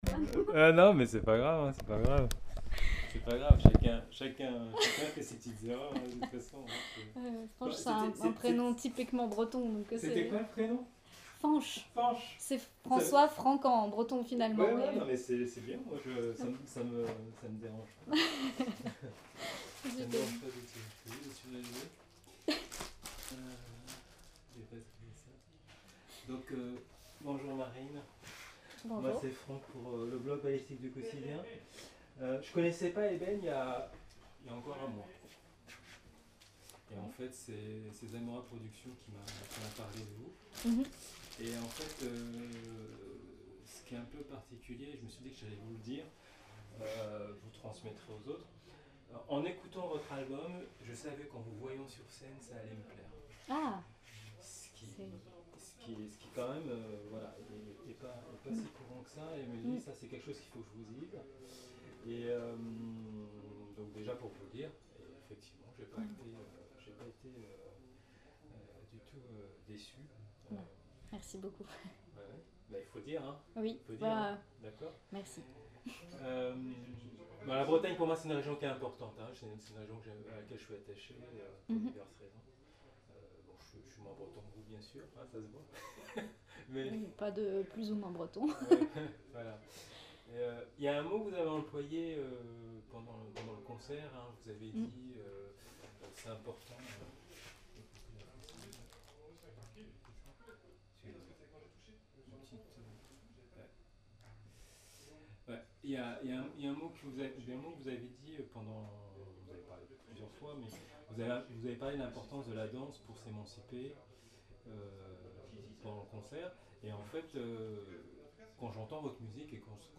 Chants polyphoniques, langue bretonne, alto cinq cordes, guitare électrique, guitare douze cordes, contrebasse, tablas, percussions.